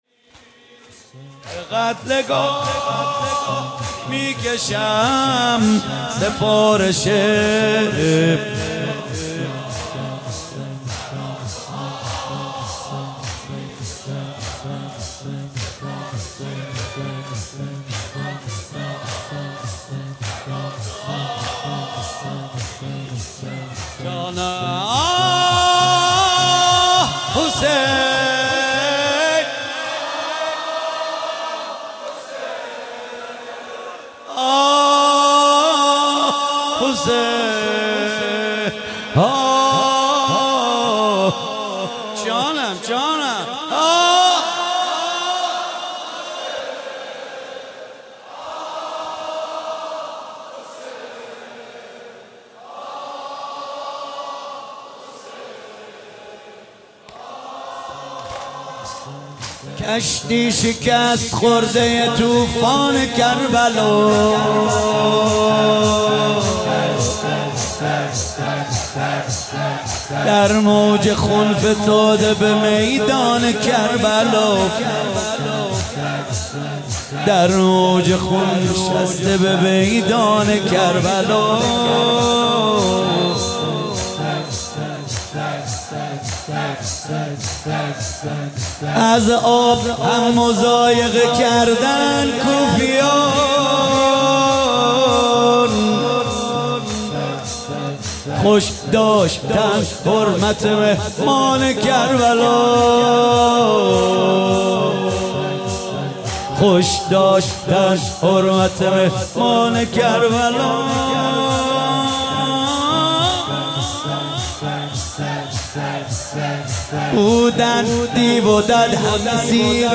شب عاشورا محرم 97 - زمینه - شب عاشوراست یه شب زیباست